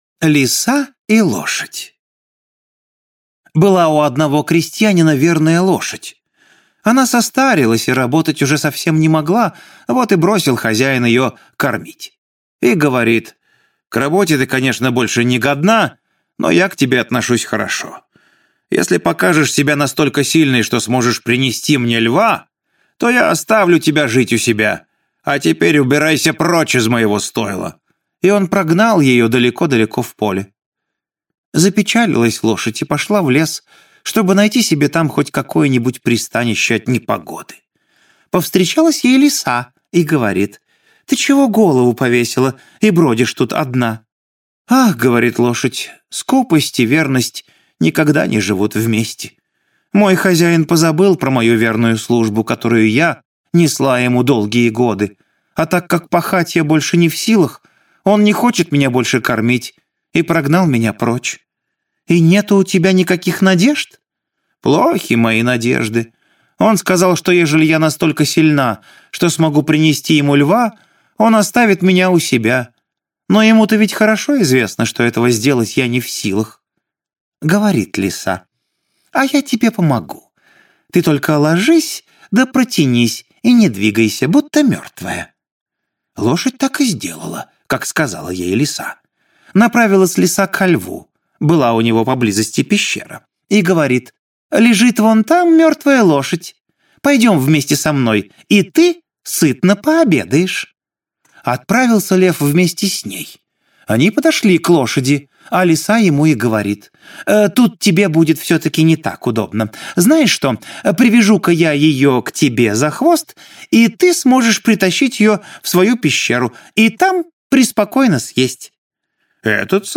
Лиса и лошадь – Братья Гримм (аудиоверсия)